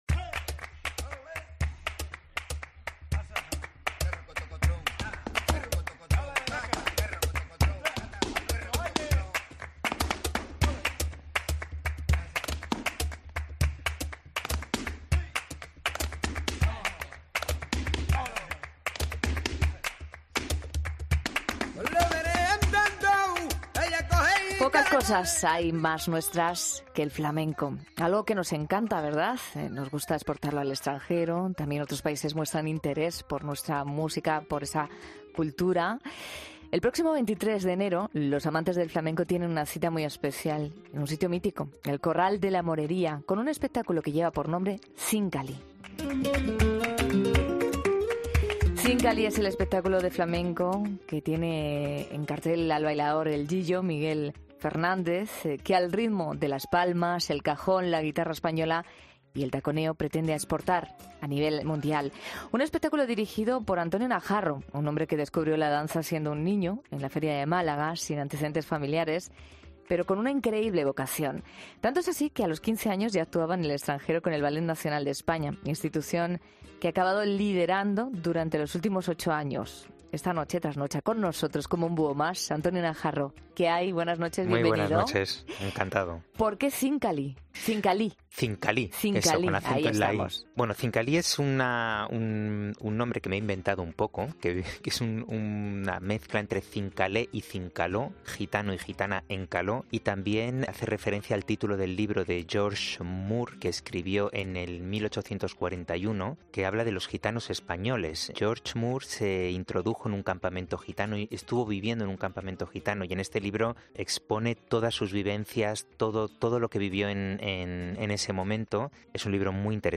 El coreógrafo madrileño ha contado en 'La Noche de COPE' su nuevo proyecto